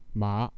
3 low (falling+)rising
[màá] ʻhorseʼ
For example, Mandarin Chinese tone 3 (low rise) is long with creaky voice, Hunanese tone 2 has breathy or chesty voice.
Mandarin-horse.wav